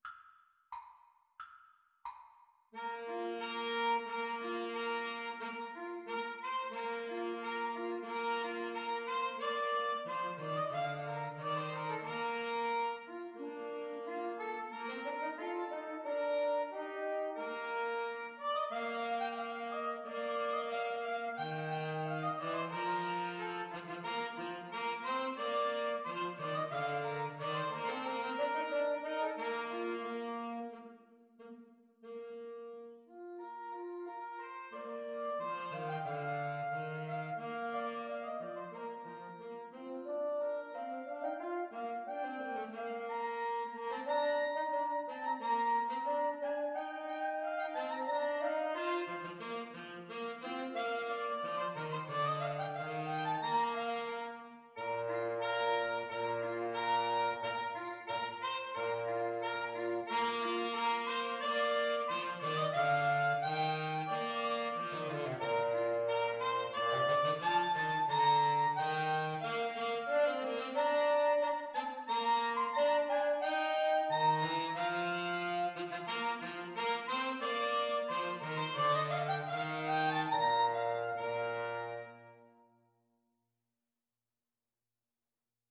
Soprano SaxophoneAlto SaxophoneTenor Saxophone
Bb major (Sounding Pitch) (View more Bb major Music for Woodwind Trio )
March ( = c. 90)